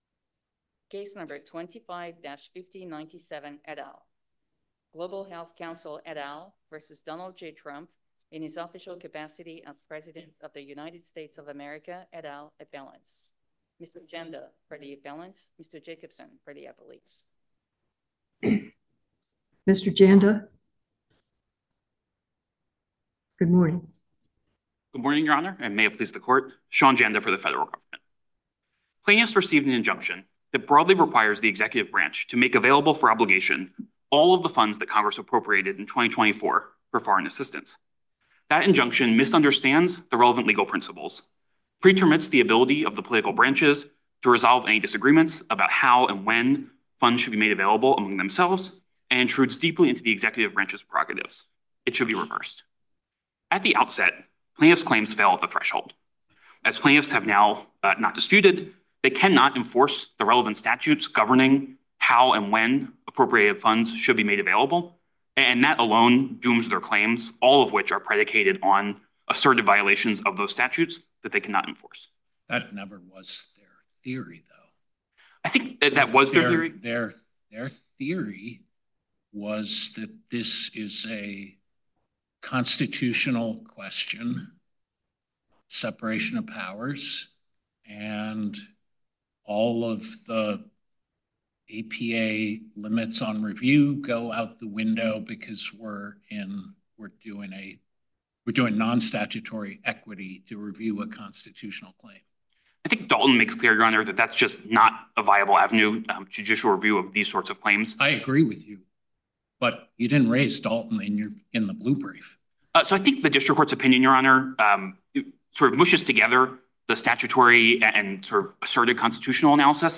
USCA-DC Oral Argument Recordings